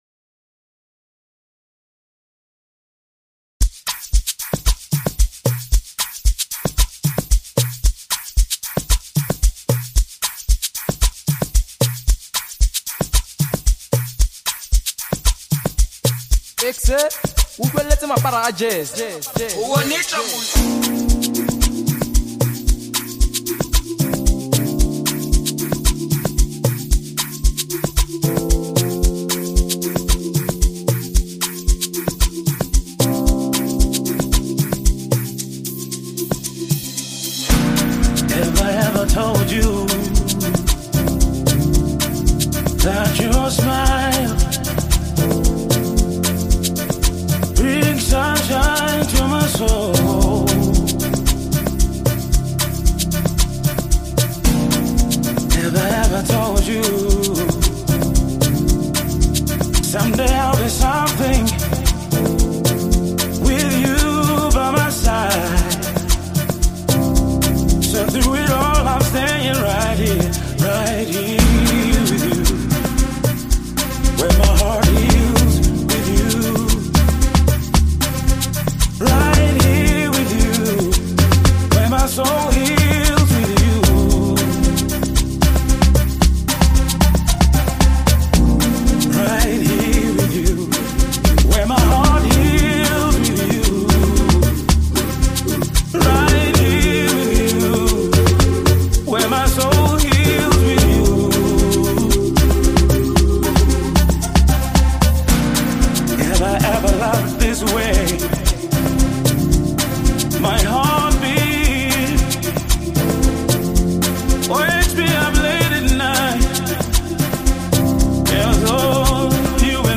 Find more Amapiano Songs